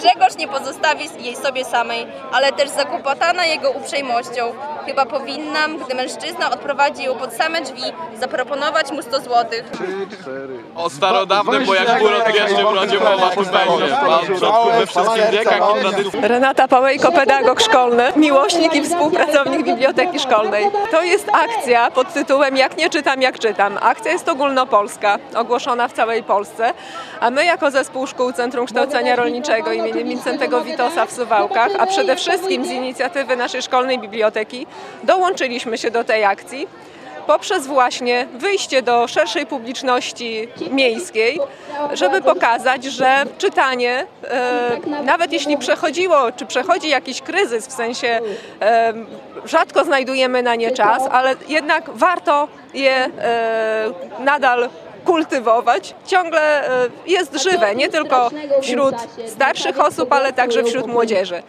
Do ogólnopolskiej akcji „Jak nie czytam, jak czytam” przyłączyło się Centrum Kształcenia Rolniczego w Suwałkach. Z tej okazji w piątek (08.06) przed południem uczniowie, pracownicy szkoły i nauczyciele czytali w Parku Konstytucji 3 Maja wybrane książki.